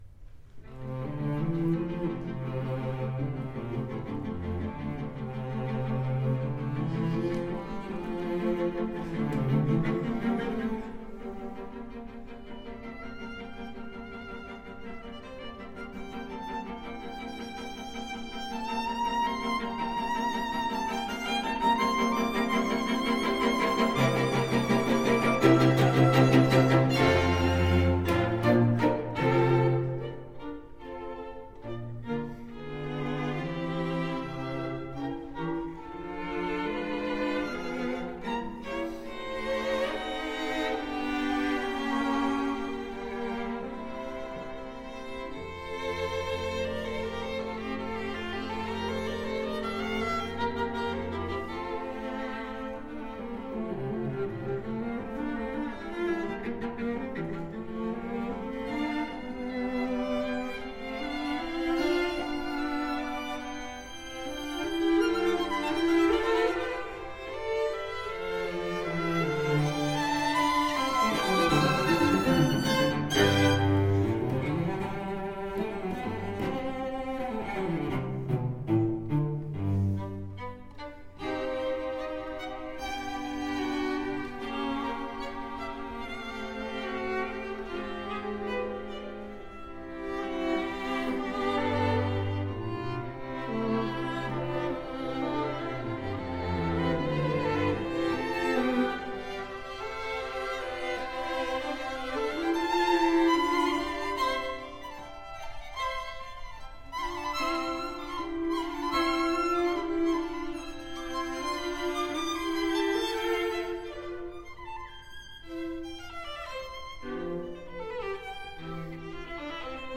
Instrument: String Quartet
Style: Classical
Audio: Boston - Isabella Stewart Gardner Museum
Audio: Borromeo String Quartet
string-quartet-7-op-59-1.mp3